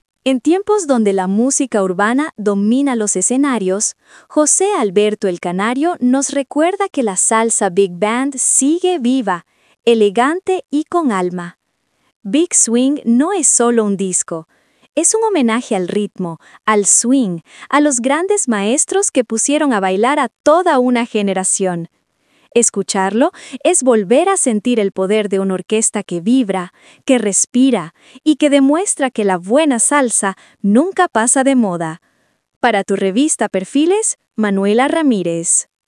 COMENTARIO EDITORIAL